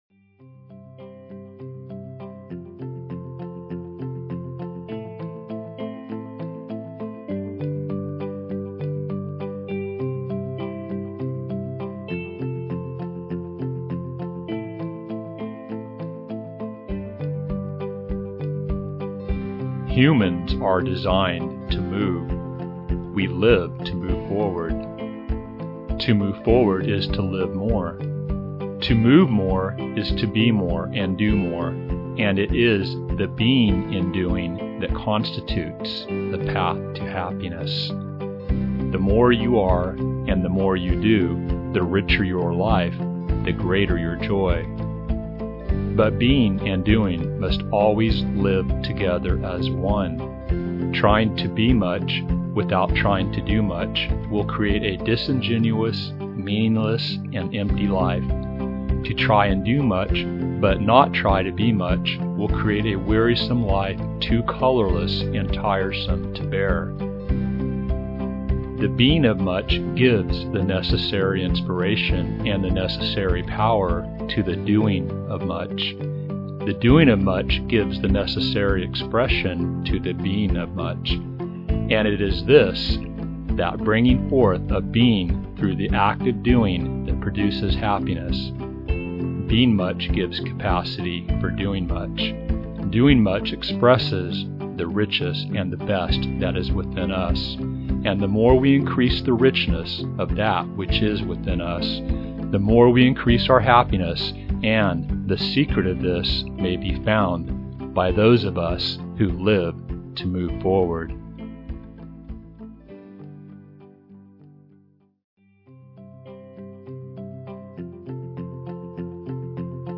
Express your inner richness through meaningful action Cultivate deeper being that fuels authentic doing Move forward in ways that expand rather than exhaust you The Doing Much Being Much Motitation is 21:08 minutes long with the message or “thought code” being repeated numerous times.